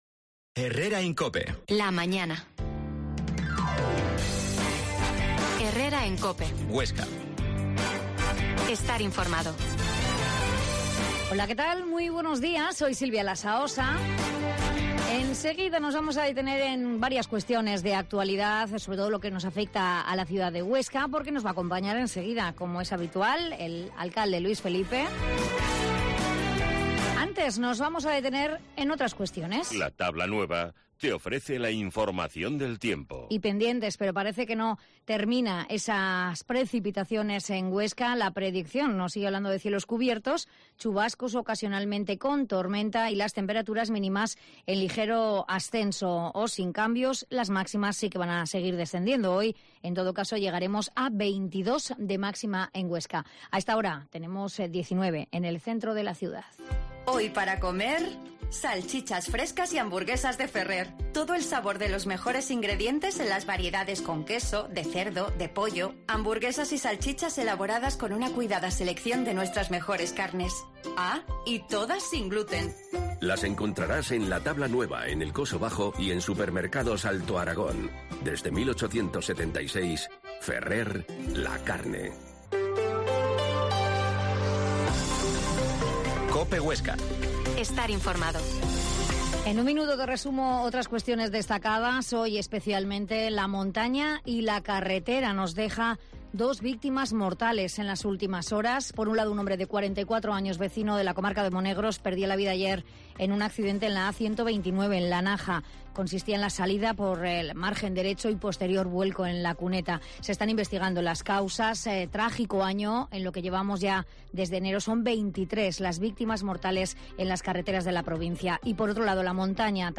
Herrera en COPE Huesca 12.50h Entrevista al alcalde Luis Felipe